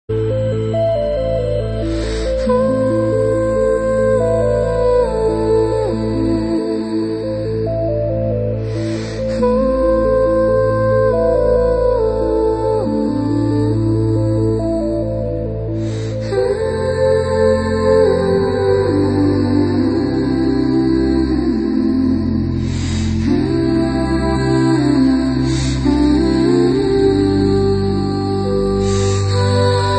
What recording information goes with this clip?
[Lo-Fi preview]